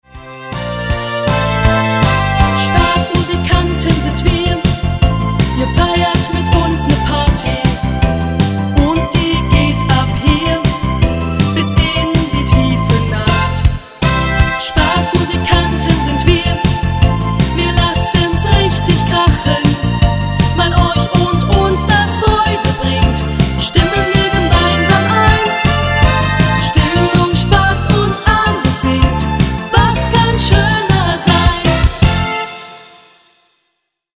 volkstümlichen Schlager - Stimmungsmusik